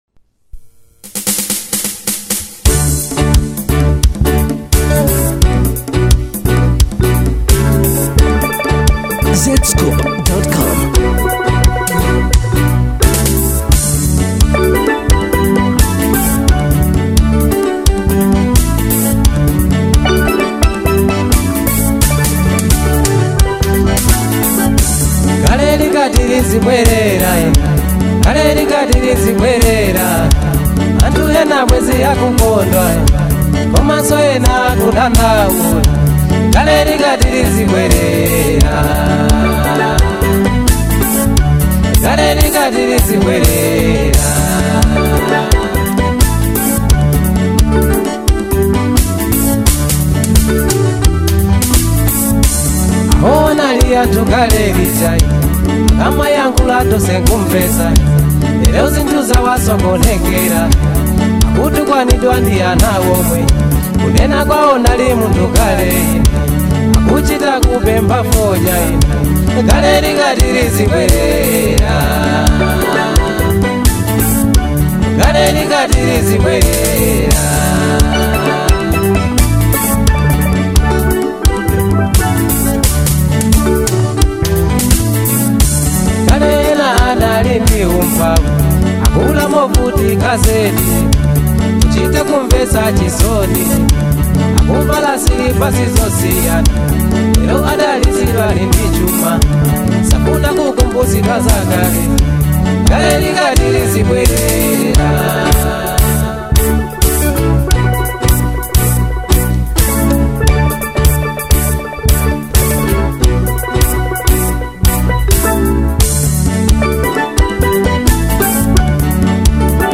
Download Latest Malawian Music